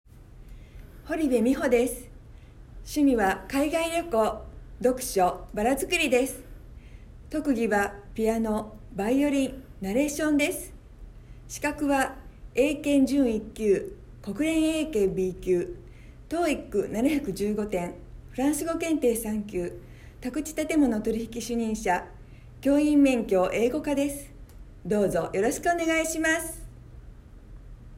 出⾝地・⽅⾔ 大阪府・大阪弁、名古屋弁
ボイスサンプル